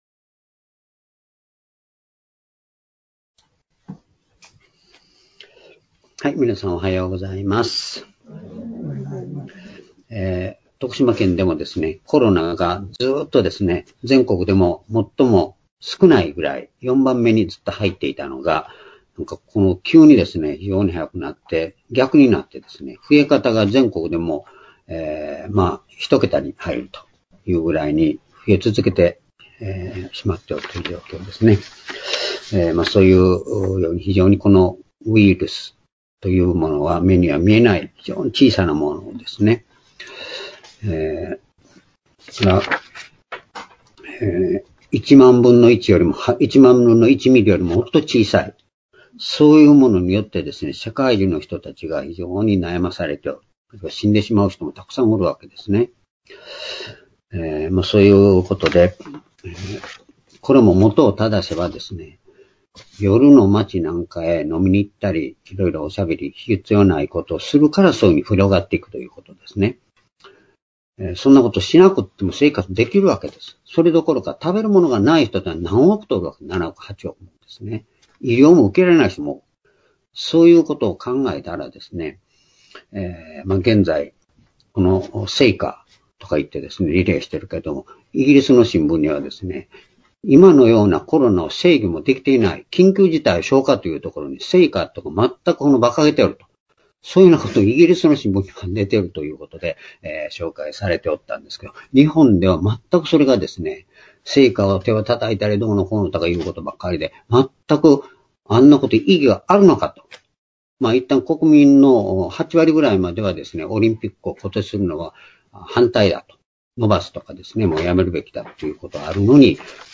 主日礼拝日時 2021年4月18日（主日礼拝） 聖書講話箇所 「宝は すべてキリストの内にあり」 コロサイ書２章3節～5節 ※視聴できない場合は をクリックしてください。